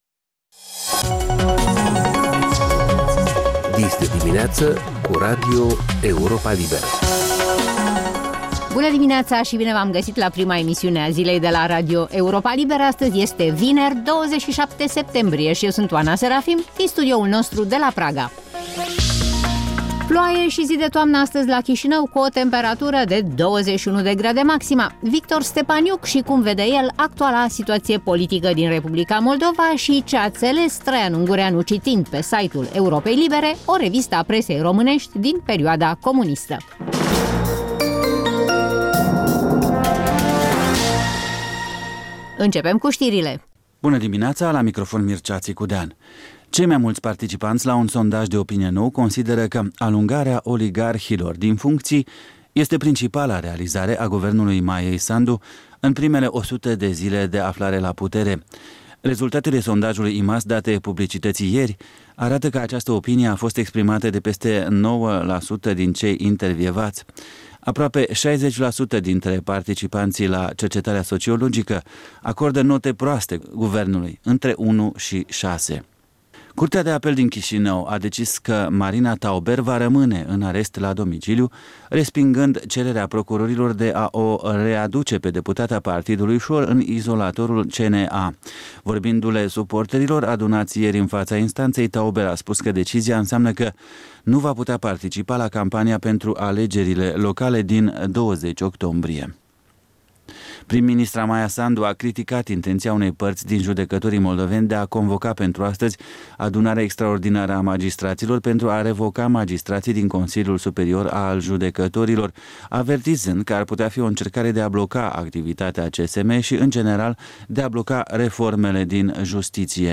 Ştiri, informaţii, interviuri, corespondenţe.